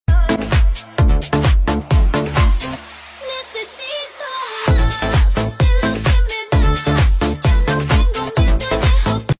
Category: Meme Soundboard